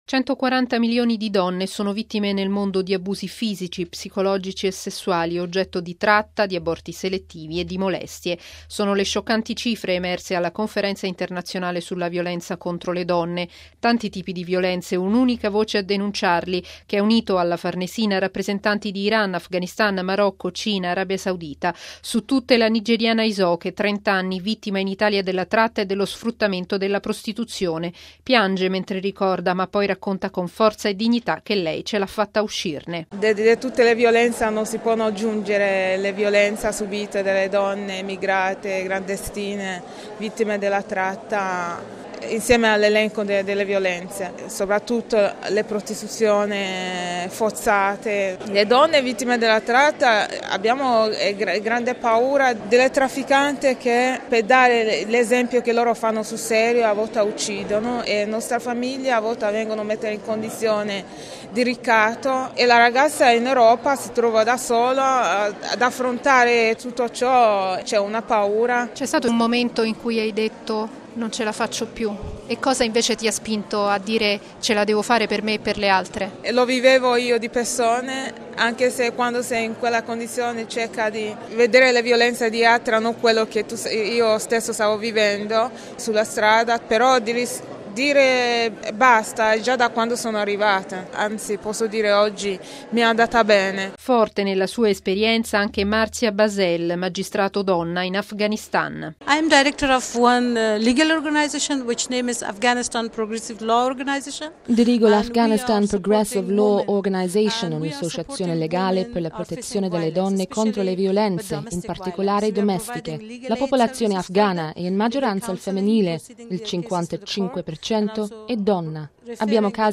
Piange mentre ricorda, ma poi racconta con forza e dignità che lei ce l’ha fatta a uscirne: